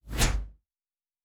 pgs/Assets/Audio/Sci-Fi Sounds/Movement/Synth Whoosh 3_1.wav at master
Synth Whoosh 3_1.wav